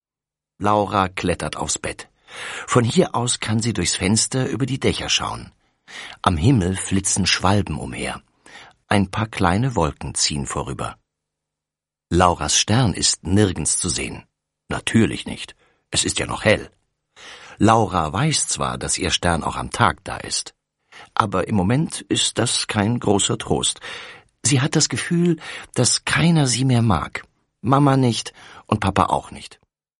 Ravensburger Laura und die Lampioninsel ✔ tiptoi® Hörbuch ab 5 Jahren ✔ Jetzt online herunterladen!